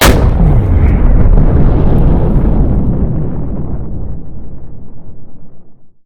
rocket.ogg